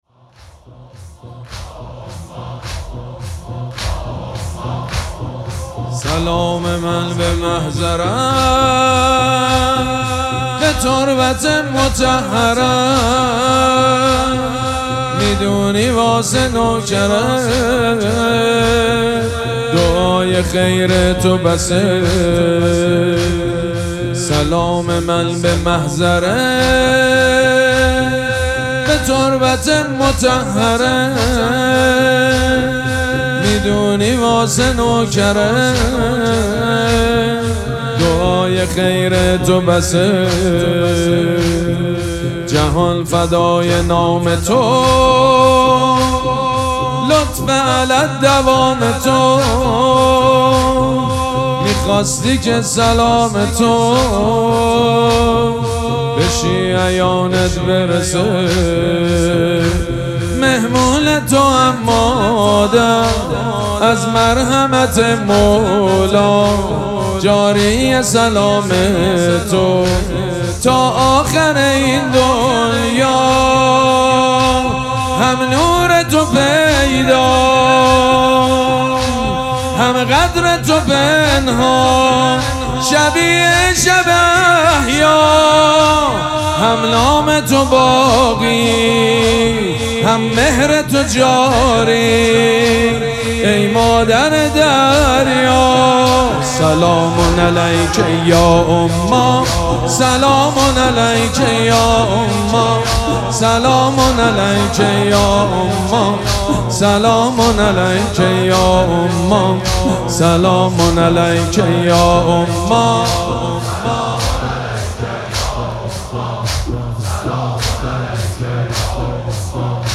مداحی زمینه